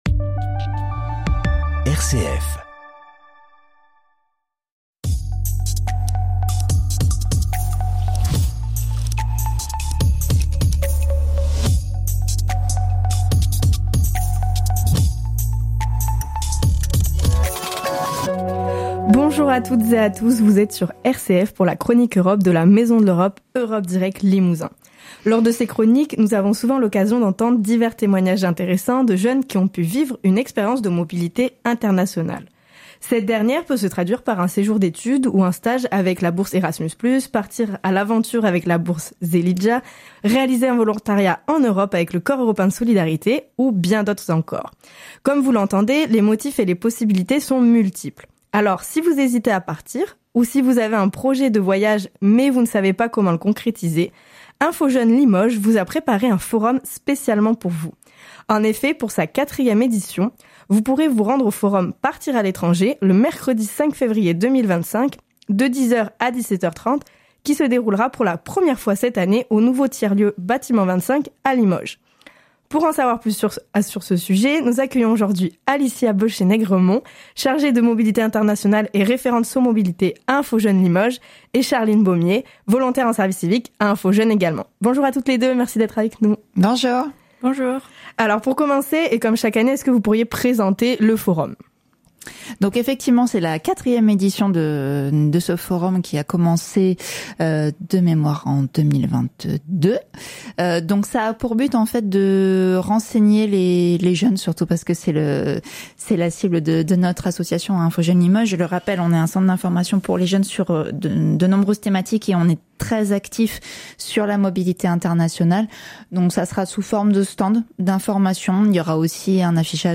Forum « Partir à l’étranger » – 5 février 2025 – L’interview | Maison de L'Europe